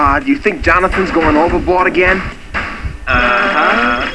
Note: All of these sounds are spoken by the cartoon characters, NOT the real guys, unless otherwise noted.
an NKOTB cartoon